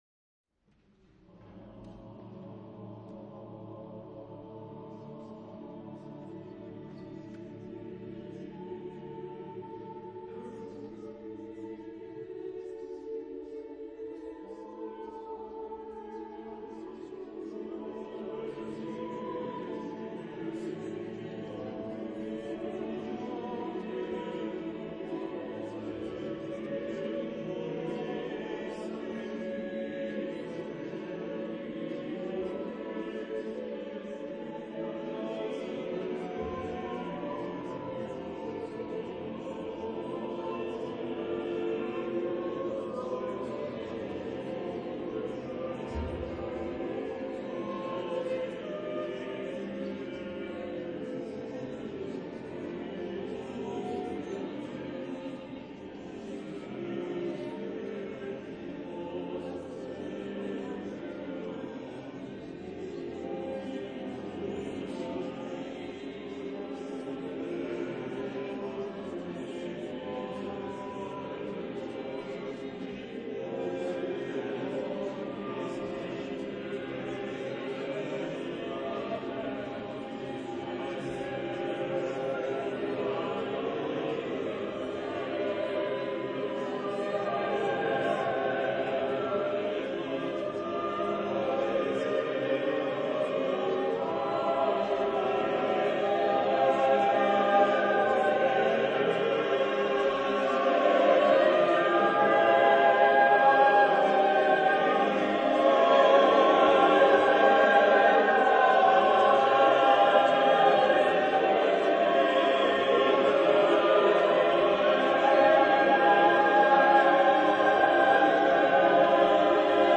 Genre-Stil-Form: Motette ; geistlich
Chorgattung: SSAATTBB  (8 gemischter Chor Stimmen )
Tonart(en): frei